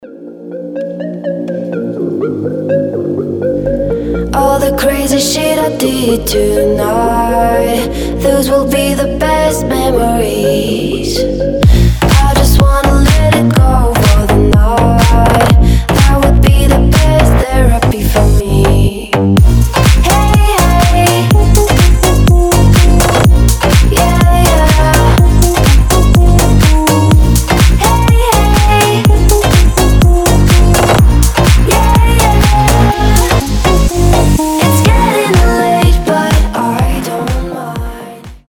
• Качество: 320, Stereo
громкие
зажигательные
веселые
Cover
slap house